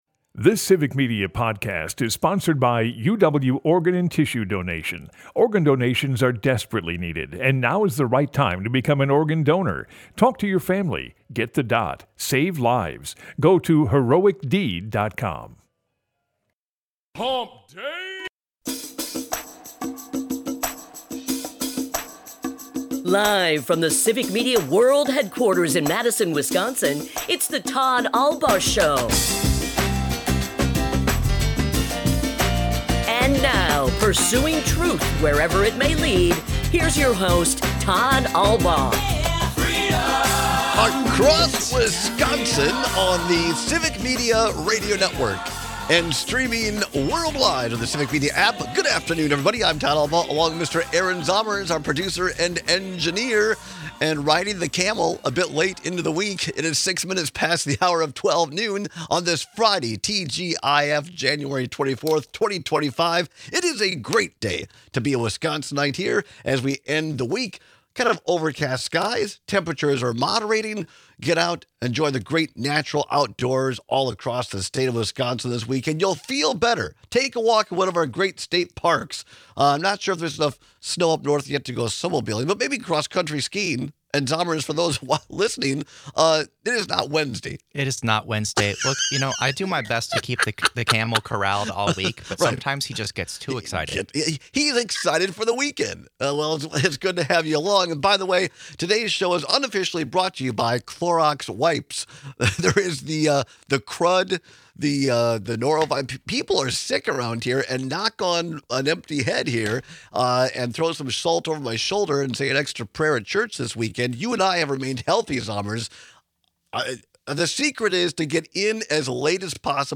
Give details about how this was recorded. Broadcasts live 12 - 2p across Wisconsin.